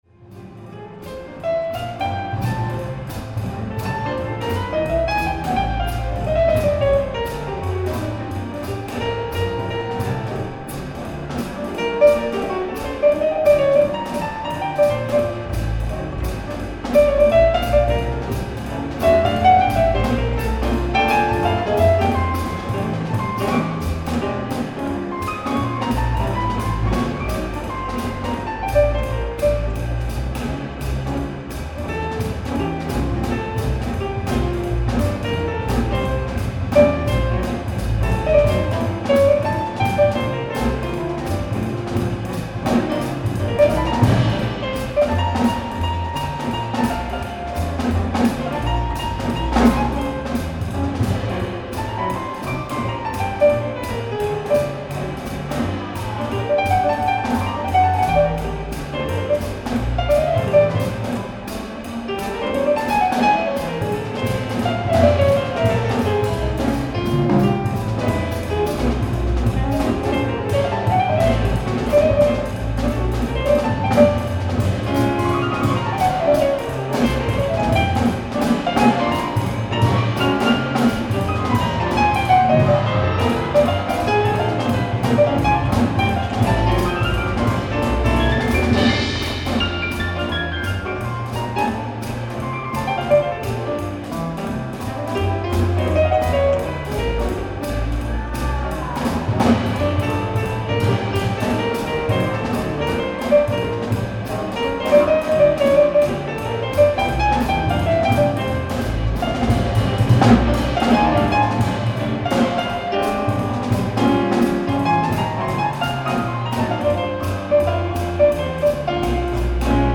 ライブ・アット・シンフォニーホール、ボストン 03/08/2002
※試聴用に実際より音質を落としています。